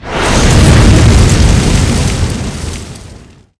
effect_wind_0012.wav